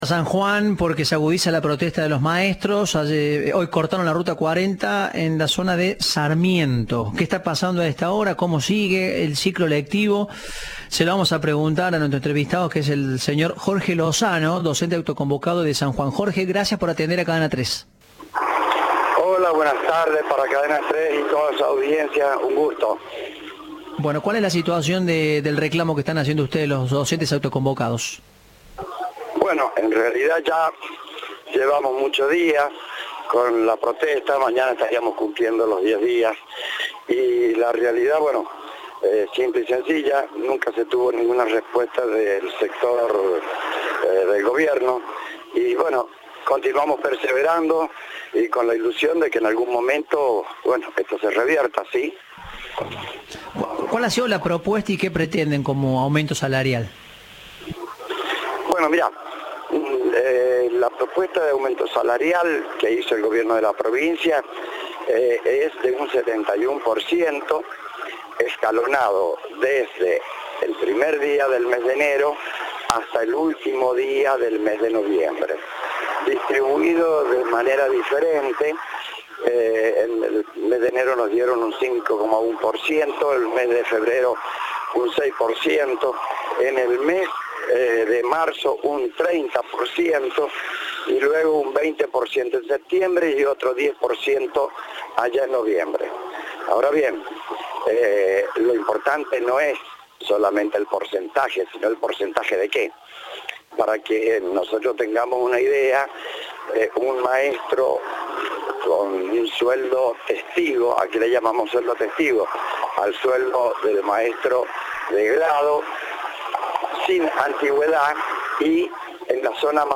Informe
Entrevista